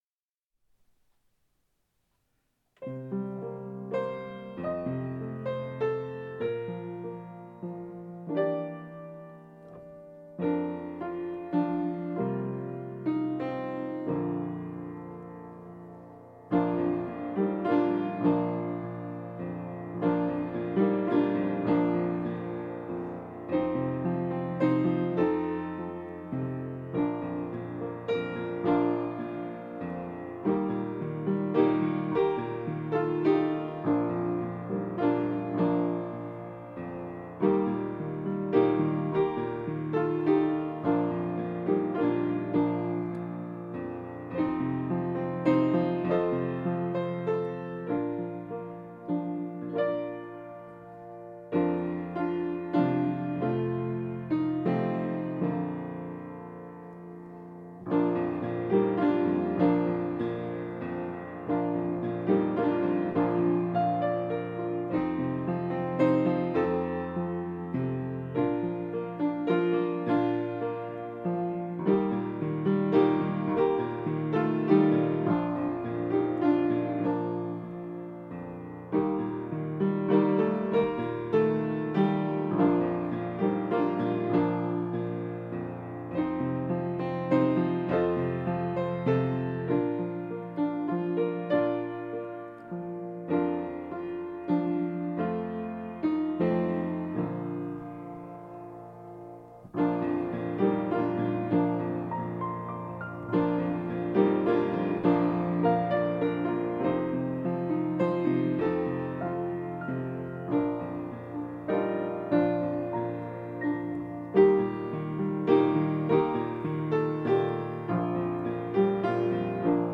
Here are Christmas songs for corporate singing.
all piano (3 verses: hymnbook key Bb)Download